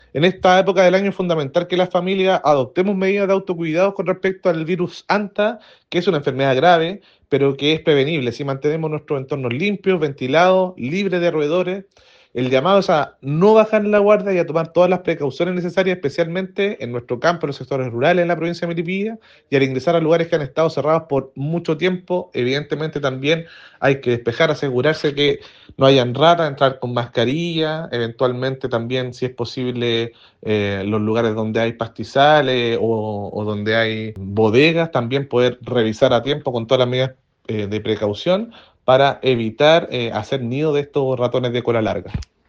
“En esta época del año es fundamental que las familias adopten medidas de autocuidado. El Virus Hanta es una enfermedad grave, pero prevenible si mantenemos nuestros entornos limpios, ventilados y libres de roedores. El llamado es a no bajar la guardia y a tomar todas las precauciones necesarias, especialmente en sectores rurales de nuestra Provincia de Melipilla, y al ingresar a lugares que han estado cerrados por mucho tiempo”, señaló el Delegado Provincial, Bastián Alarcón.
AUDIO-Hanta-Prevencion-Delegado-Alarcon.mp3